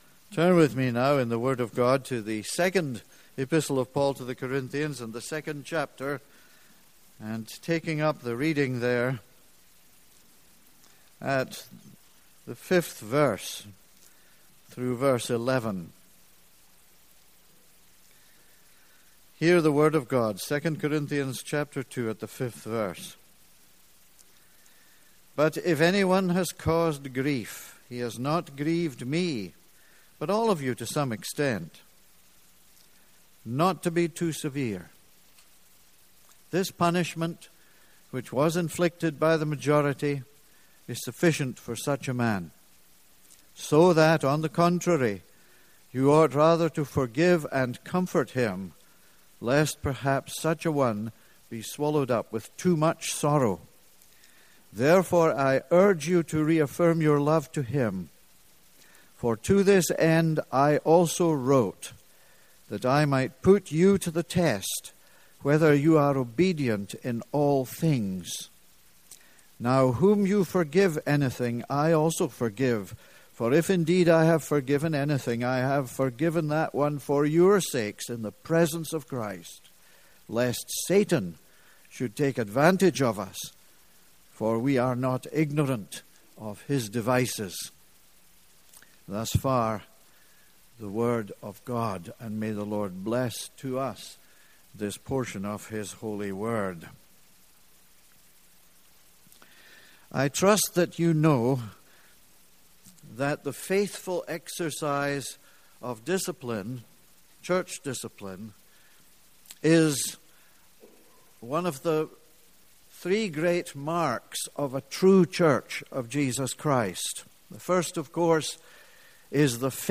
This is a sermon on 2 Corinthians 2:5-11.